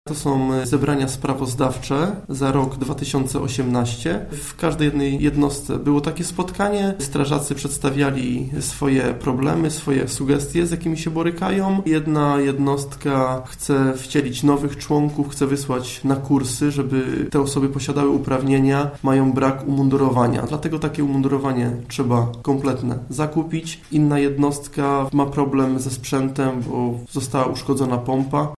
– Było mi o tyle łatwiej, że jestem Gminnym Komendantem OSP – mówi Marcin Reczuch, wójt gminy Dąbie.